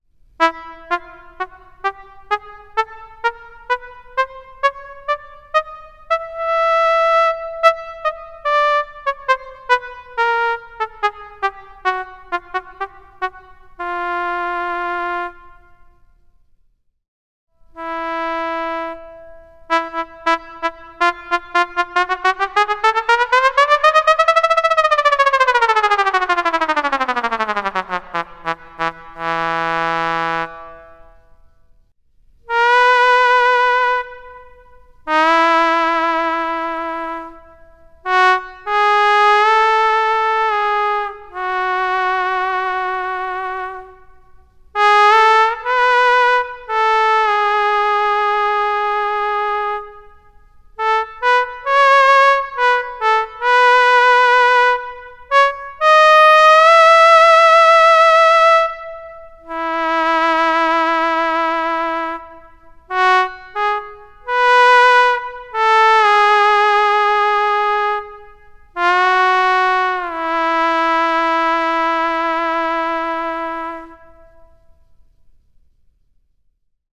[EDIT] You can listen to the new velocity (followed by a fairly sharp knee) in the trumpet_Bb preset here: